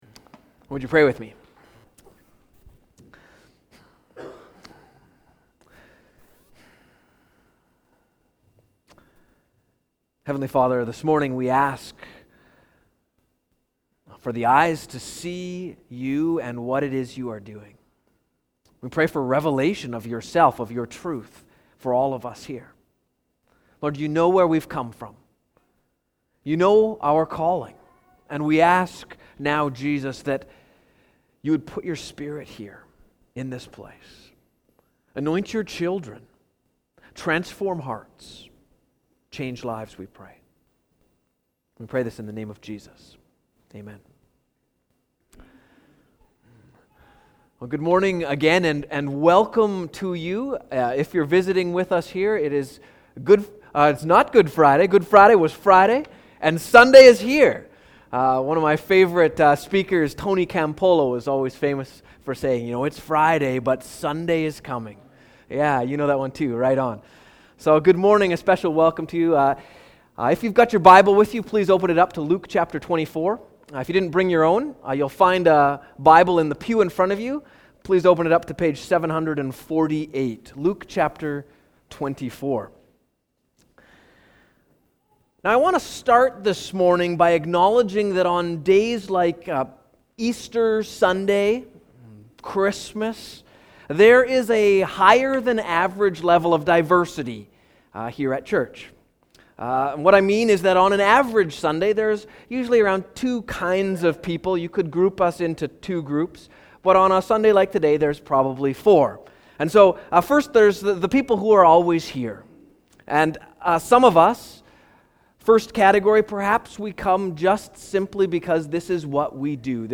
Sermons | Fort George Baptist Church